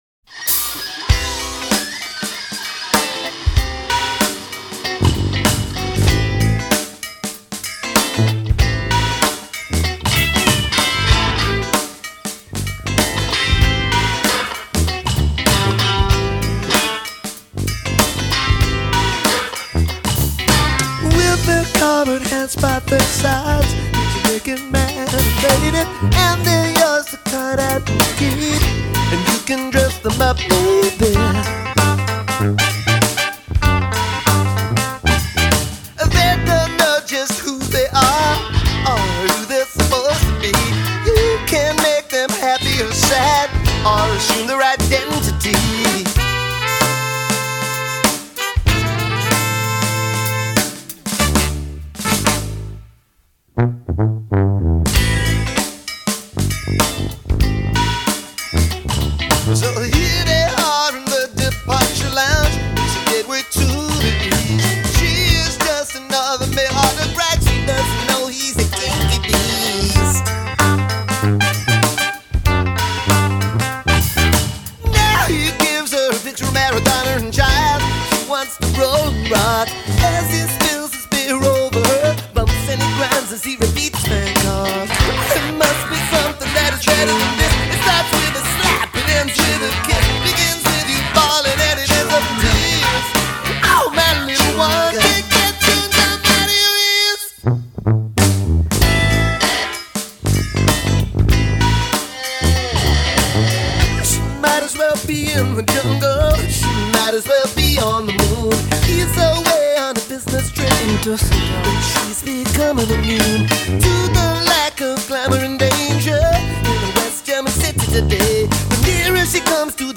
but musically it’s just giddy.